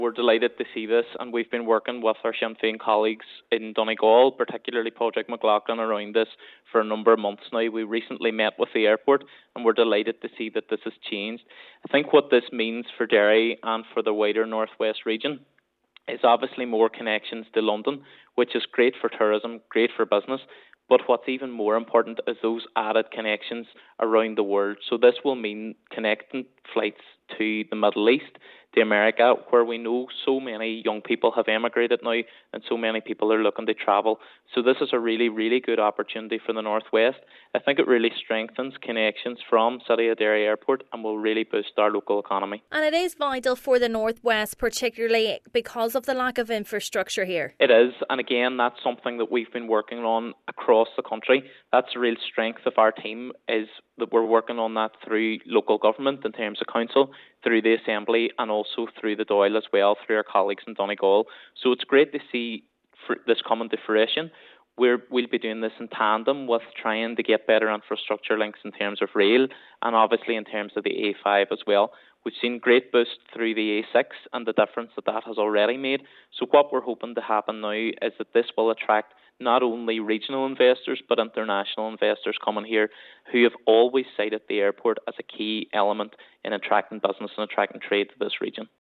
The Foyle MLA says the service will be key in enhancing infrastructure in the region: